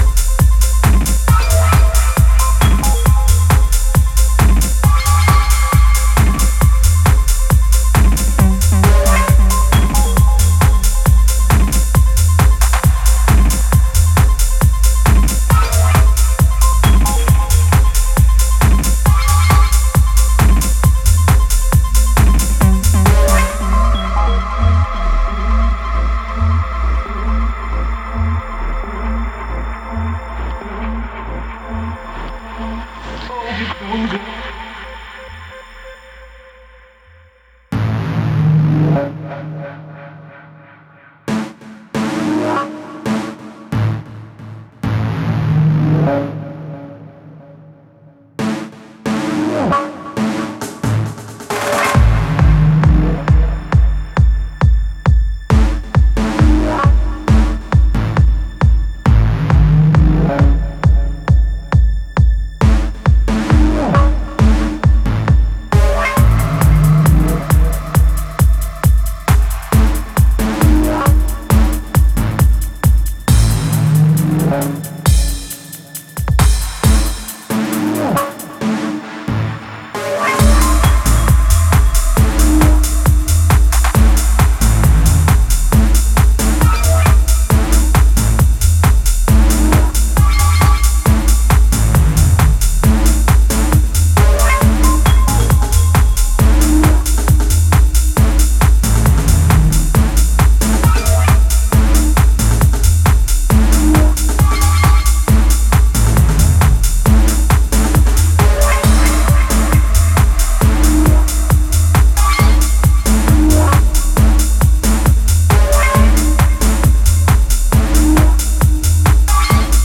4 house and techno club-bumping collaborations